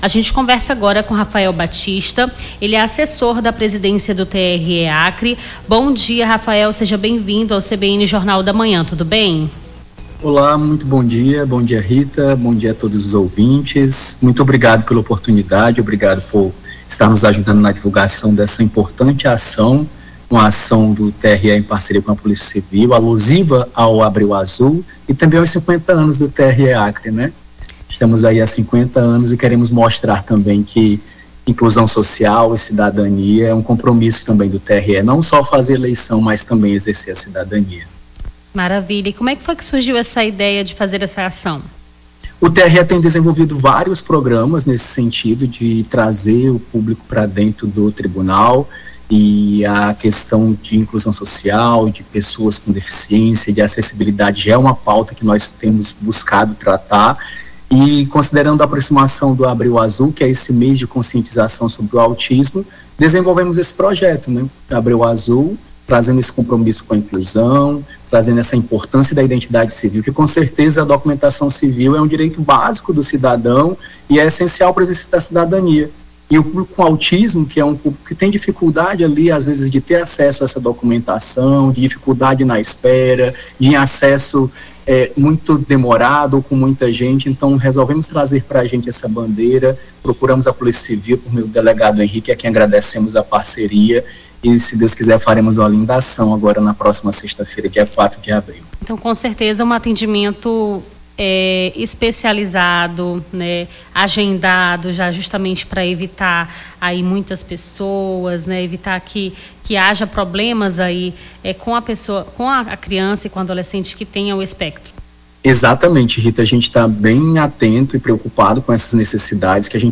Nome do Artista - CENSURA - ENTREVISTA (ACAO TRE AUTISMO) 02-04-25.mp3